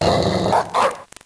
Cri de Mastouffe dans Pokémon Noir et Blanc.